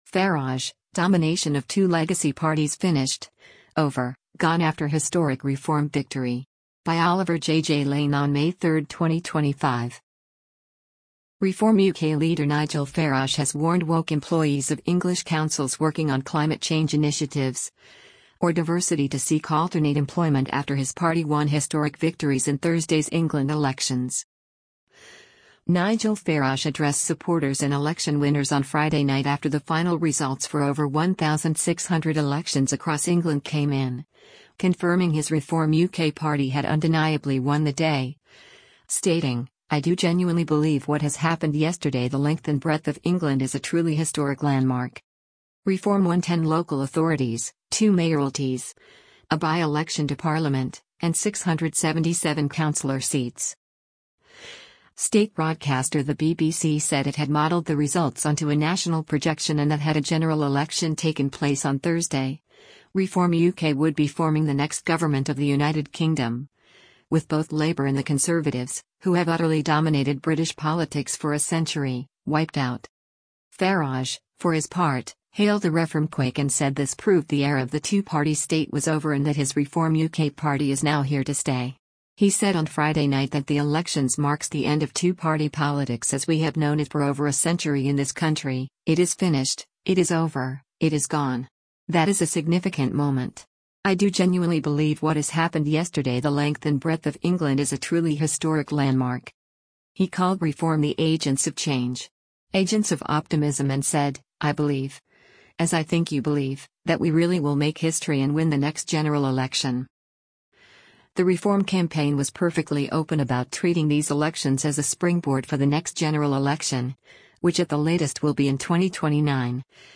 PADDOCK WOOD, ENGLAND - MAY 02: Leader of Reform UK Nigel Farage addresses supporters and